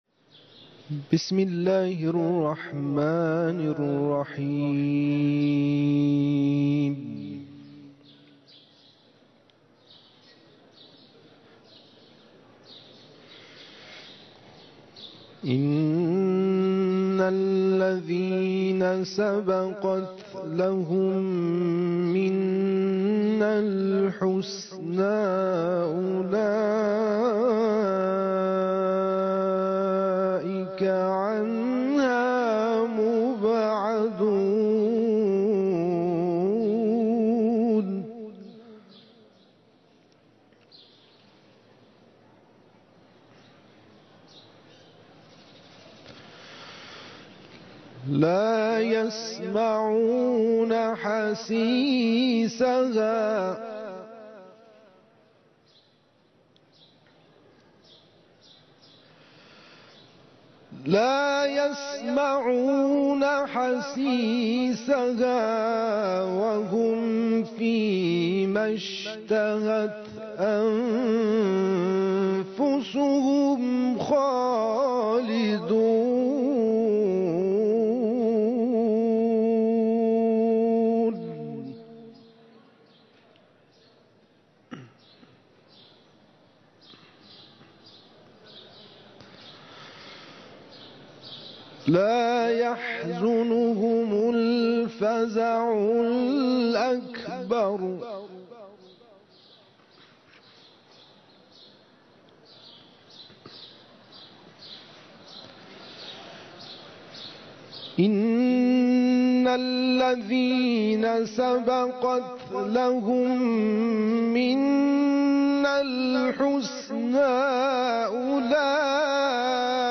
تلاوت مجلسی
نیمه شعبان سال 1400 | مسجد مقدس جمکران